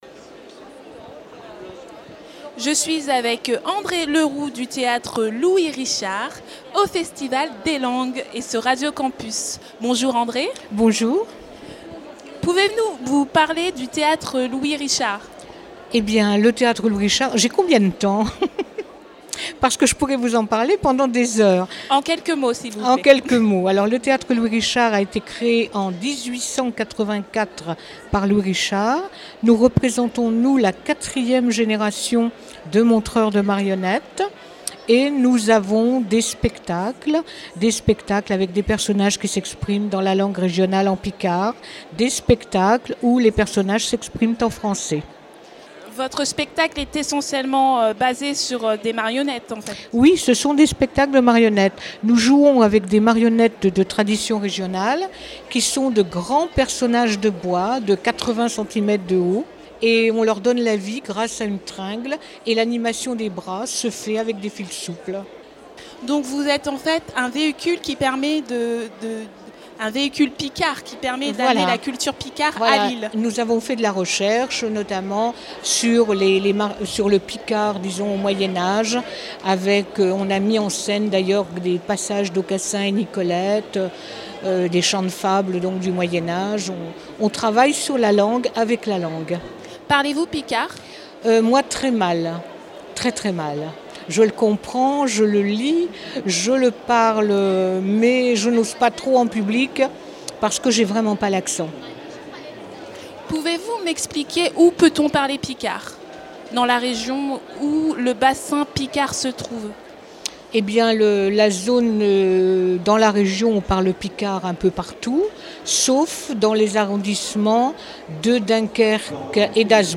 à la CCI de Lille
Interviews réalisées pour Radio Campus